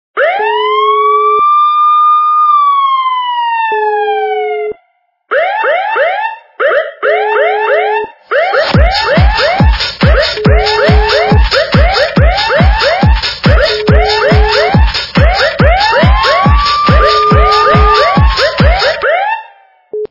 При прослушивании Звук - Модная Сирена качество понижено и присутствуют гудки.
Звук Звук - Модная Сирена